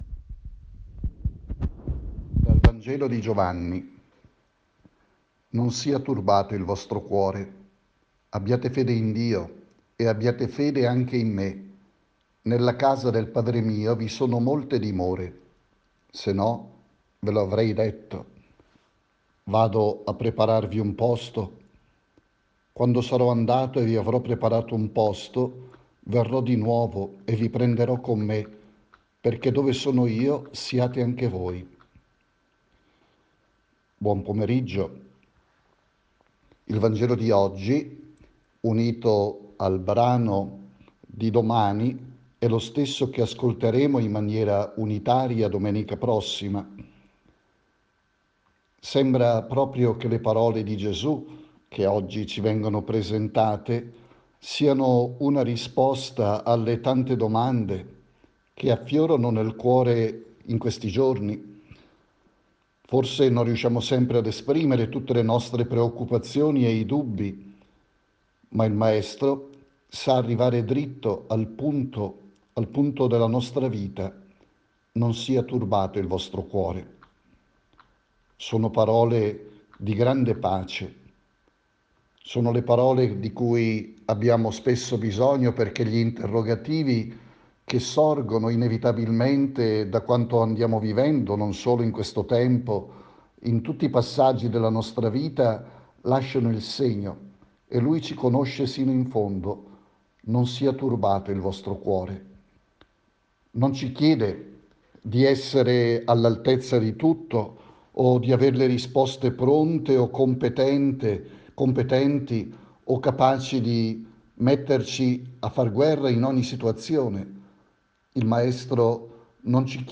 riflessione